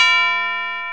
TUB BELL F3.wav